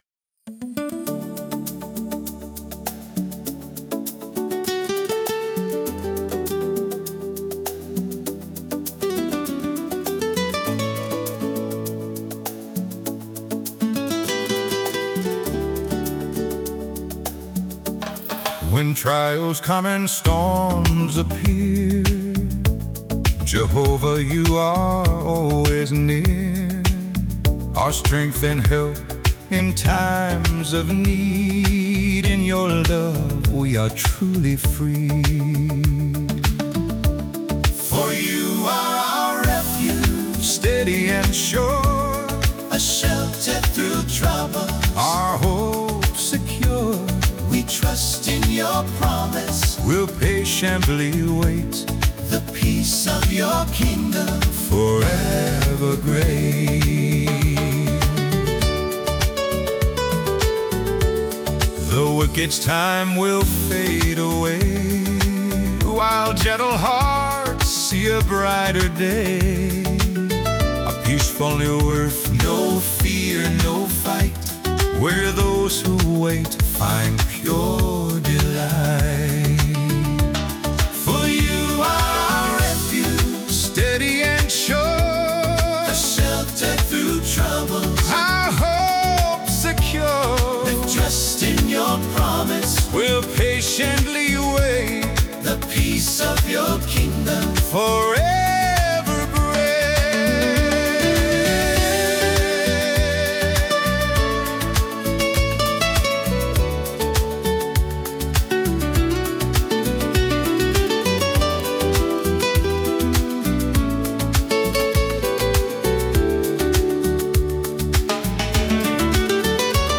Encouraging and emotional Songs